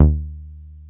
303 D#2 3.wav